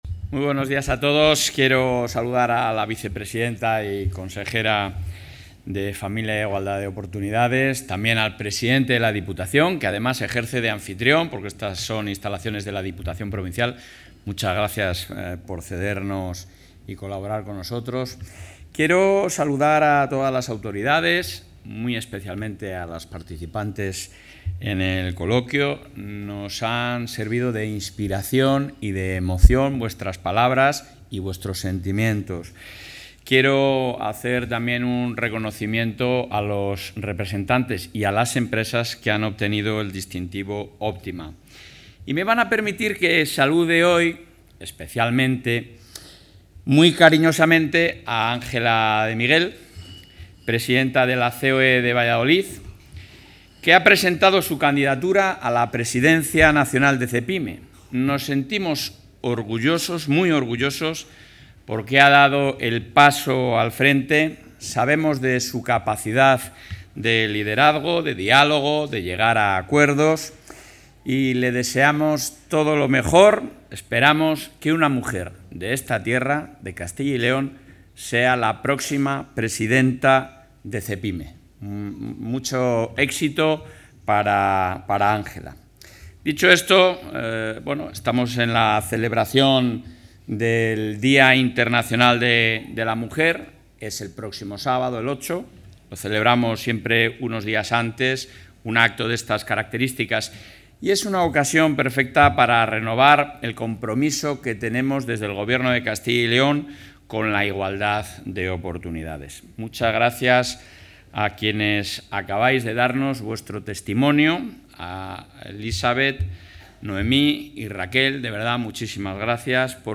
Intervención del presidente de Junta.
El presidente de la Junta de Castilla y León, Alfonso Fernández Mañueco, ha participado hoy en Valladolid en el acto con motivo del Día Internacional de la Mujer, donde ha anunciado el lanzamiento del programa ‘FP Stem’, con el objetivo de incrementar la presencia de mujeres en los grados de Formación Profesional relacionados con la ciencia y la tecnología.